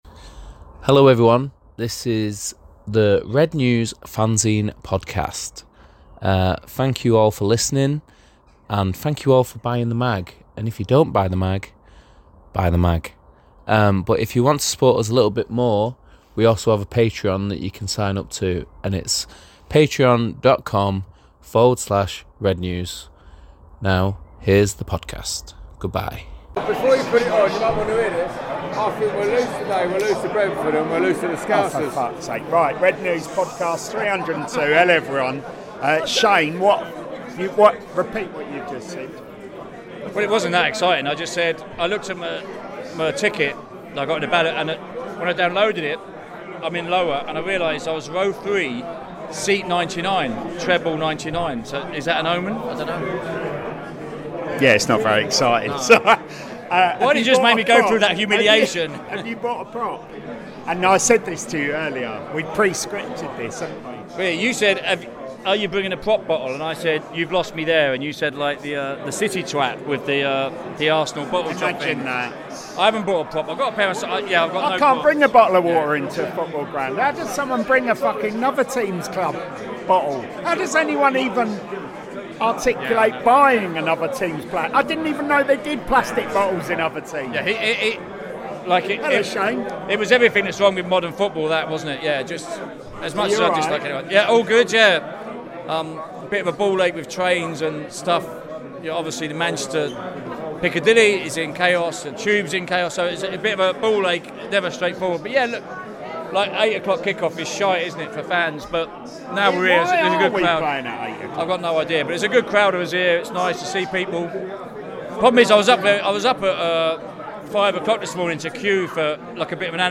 Massive win at Chelsea. We're in the pub before + after the game.
The independent, satirical Manchester United supporters' fanzine - for adults only, contains expletives, talks MUFC, or not at times, as we talk MUFC + tangents and bump into a Fulham fan on the way.